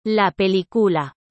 Notez que la marque qui indique l’intonation est un accent aigu sur la première lettre de la syllabe accentuée.